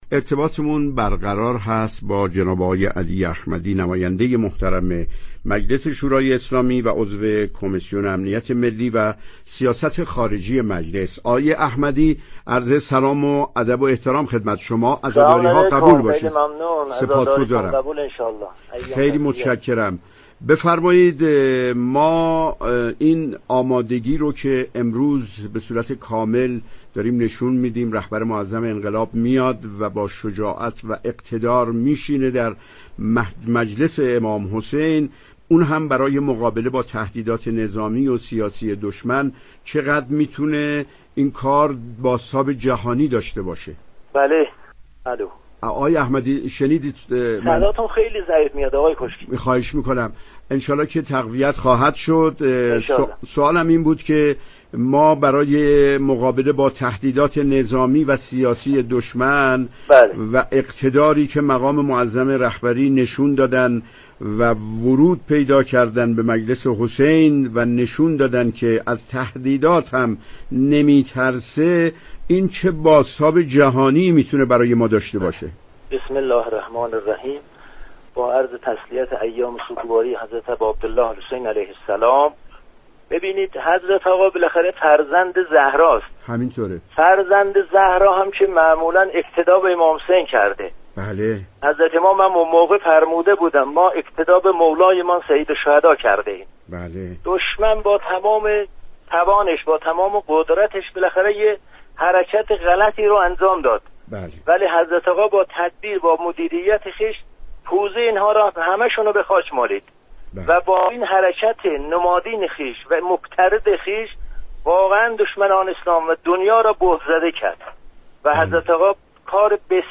به گزارش روابط عمومی رادیو صبا ، در ویژه برنامه «در امتداد عاشور»، ارتباطی با علی احمدی، نماینده مجلس شورای اسلامی و عضو كمیسیون امنیت ملی و سیاست خارجی برقرار شد.